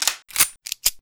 pistol_reload.wav